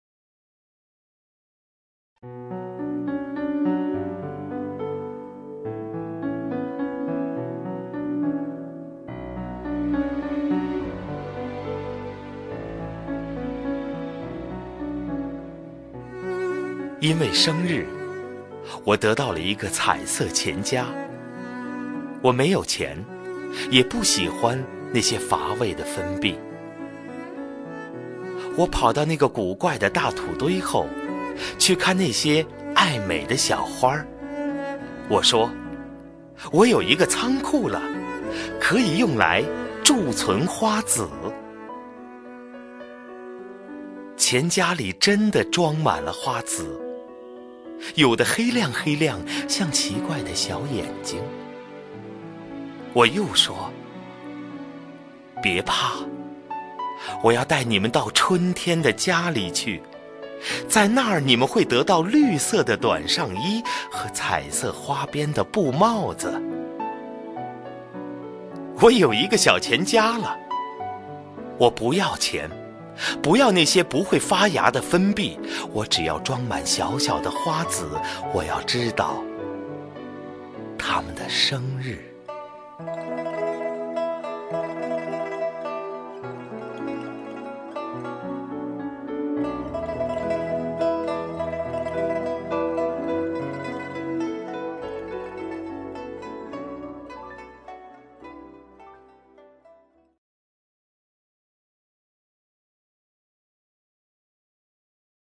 首页 视听 名家朗诵欣赏 赵屹鸥
赵屹鸥朗诵：《生日》(顾城)　/ 顾城